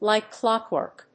アクセントlìke clóckwork